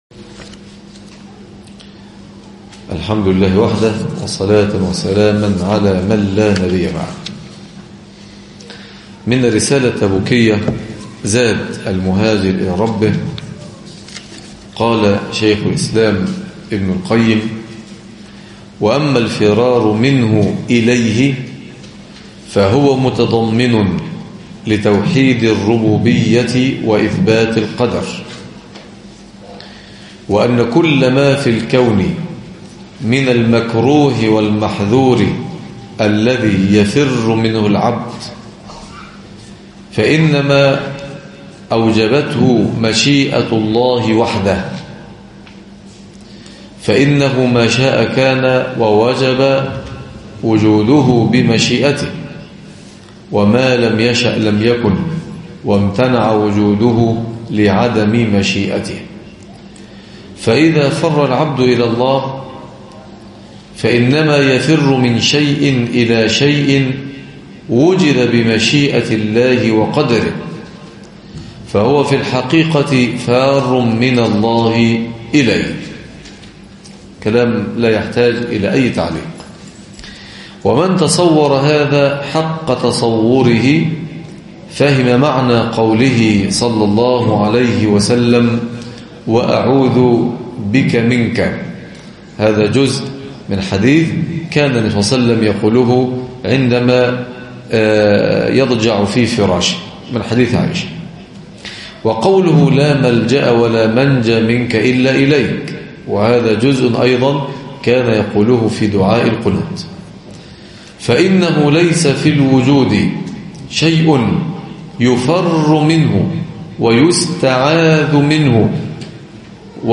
عنوان المادة الدرس السابع - تزكية- قراءة من الرسالة التبوكية لابن القيم